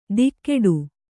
♪ dikkeḍu